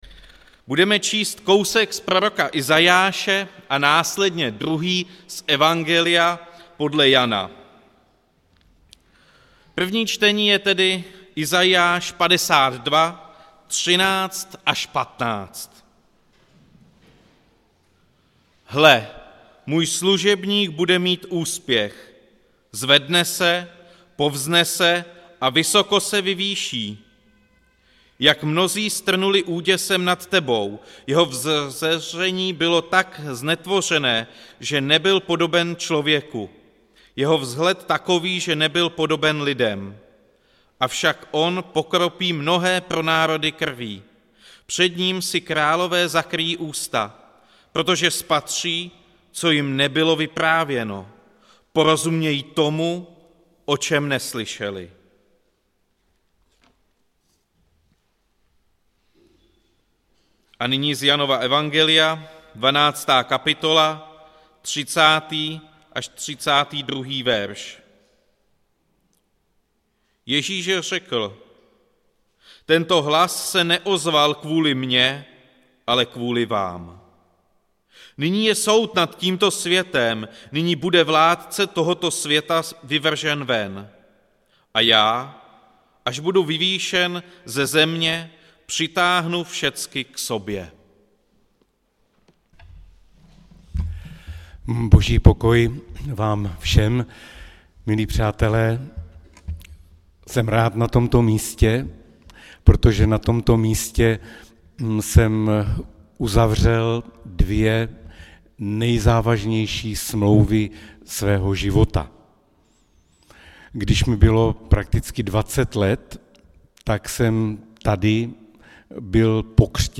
Událost: Kázání